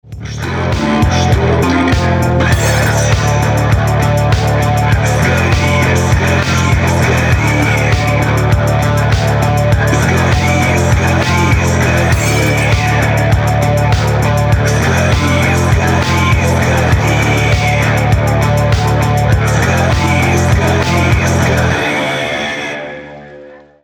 Рингтоны Альтернатива
Рок Металл Рингтоны